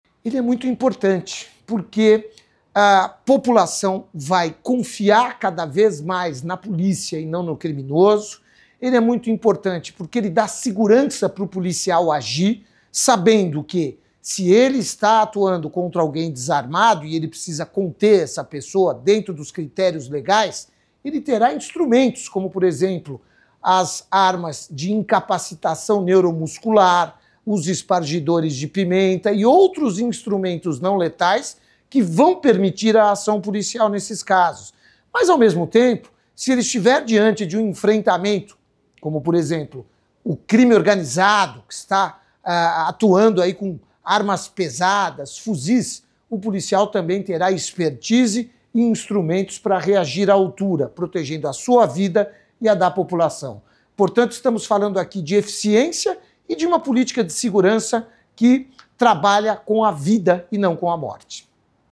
Mario Sarrubbo, secretário nacional de Segurança Pública, fala da importância do Projeto de Uso da Força para a segurança pública no Brasil — Ministério da Justiça e Segurança Pública